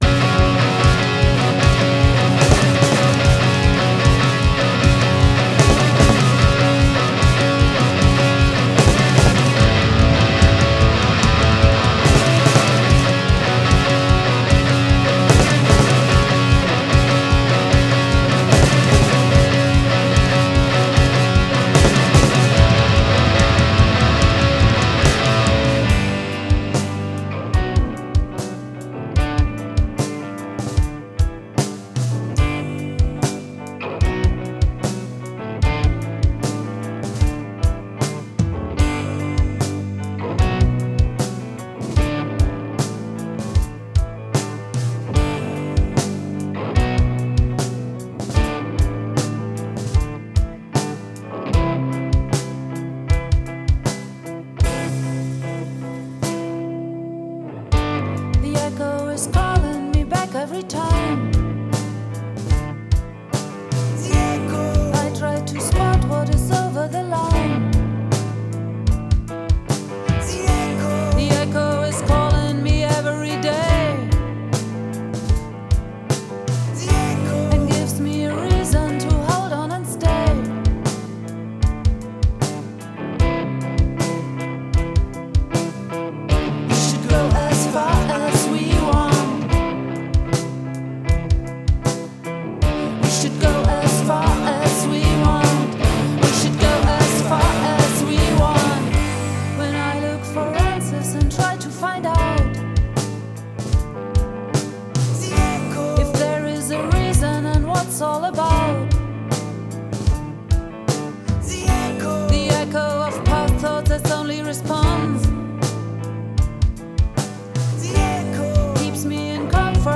et c'est assez cathartique !